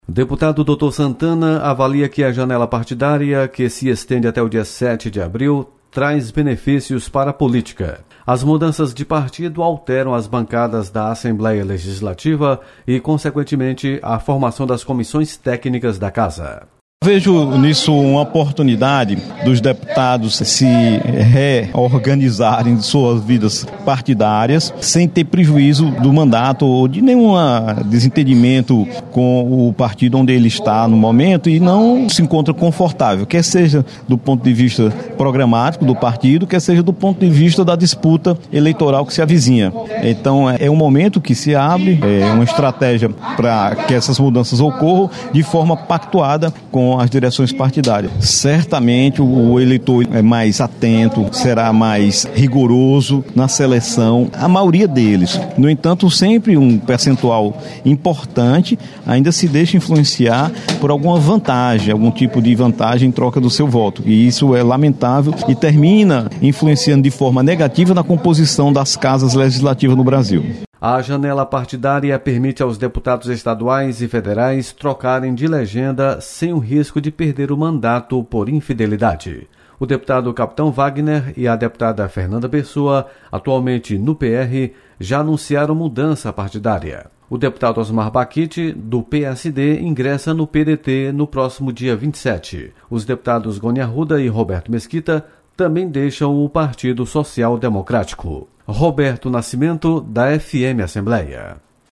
Deputado Doutor Santana avalia  janela partidária. Repórter